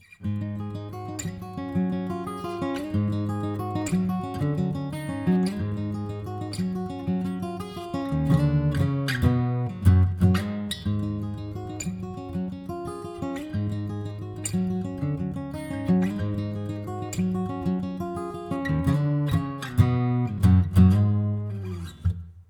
I recently picked up a AKG condenser mic for acoustic and vocal recording.
No knowledge of mic placement for acoustic guitars, no post editing. Just plug up and play with this one.